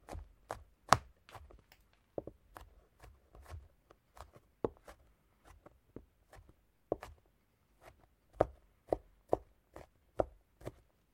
Шепот прорастающего чеснока